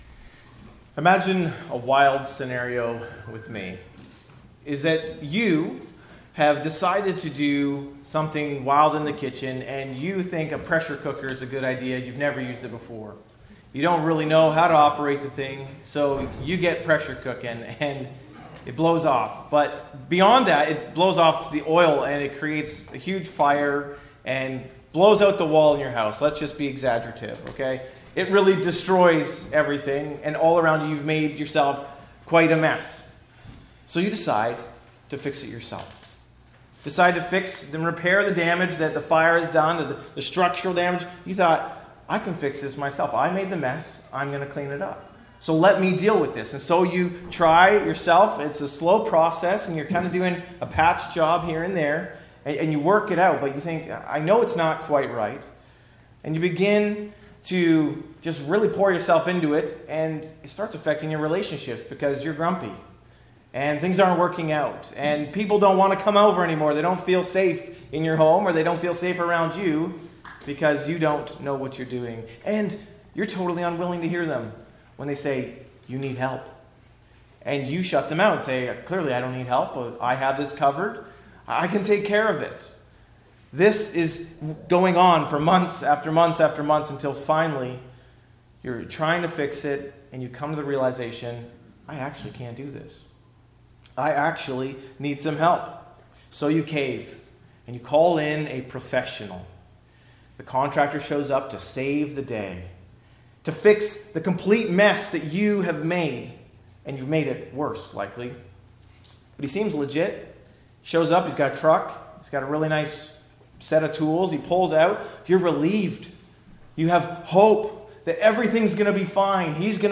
A message from the series "Christ Our Hope."